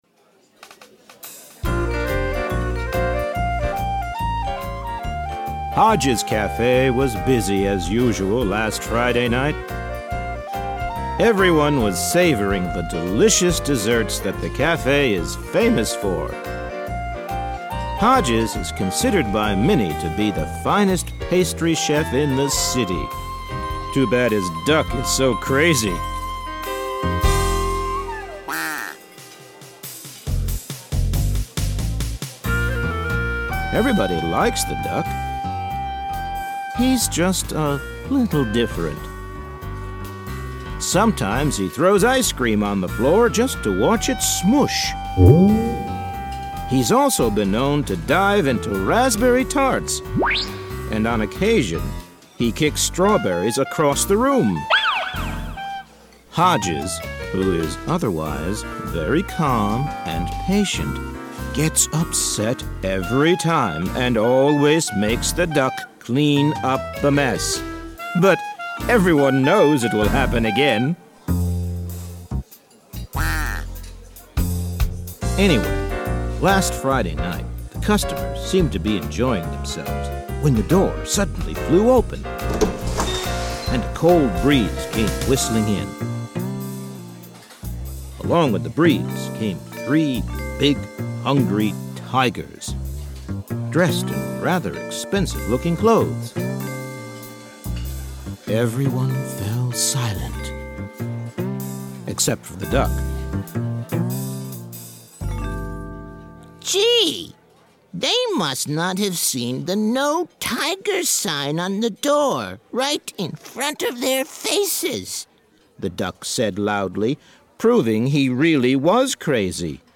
Audio clips from a few of these books are included below the following video clips.